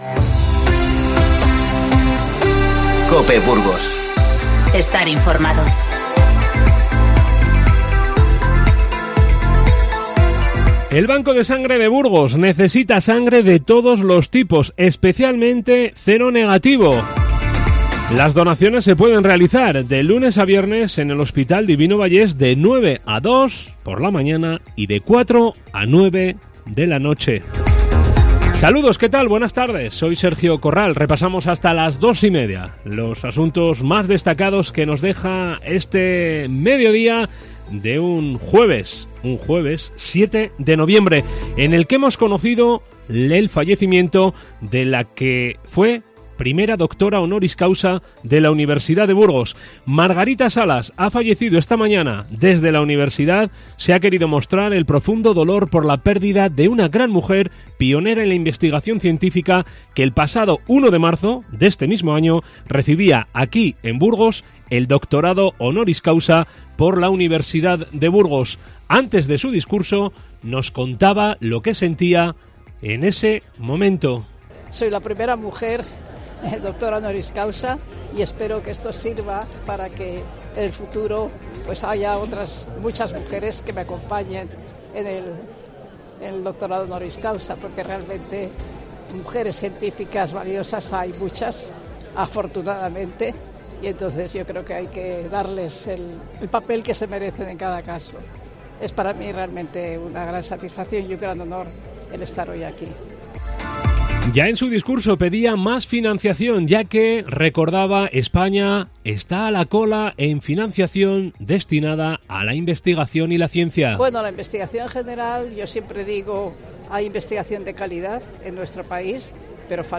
INFORMATIVO Mediodía 7-11-19